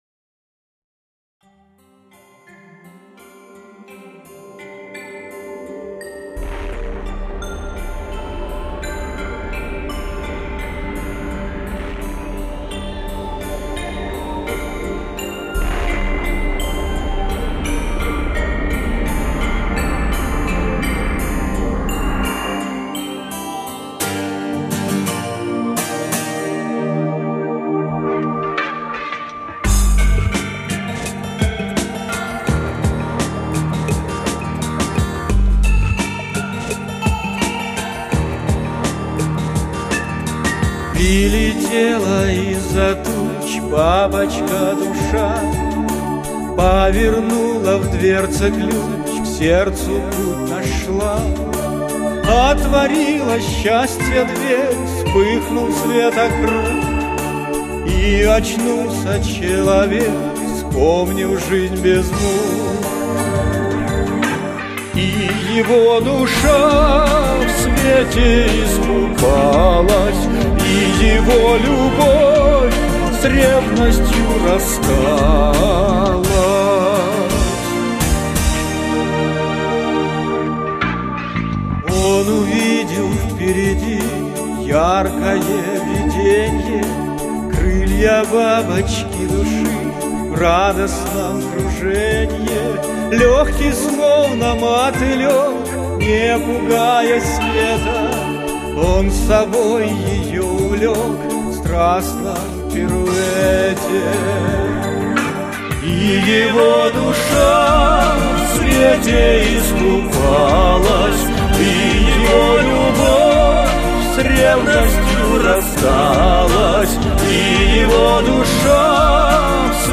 Лёгкая и спакойная мелодия